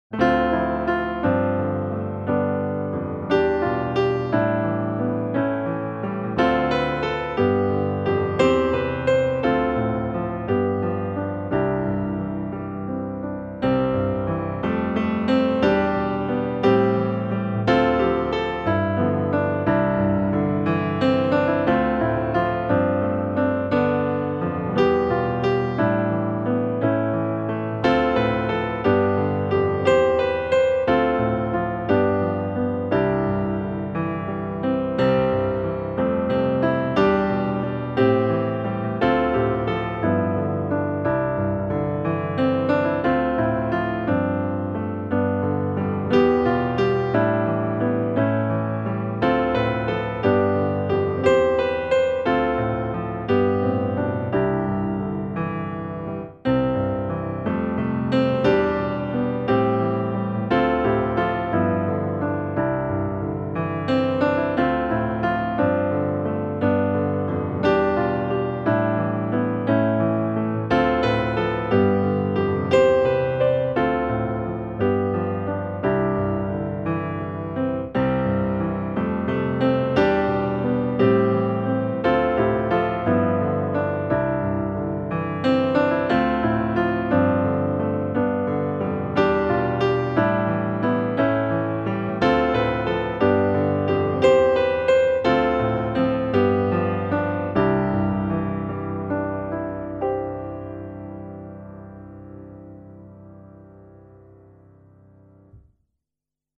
musikbakgrund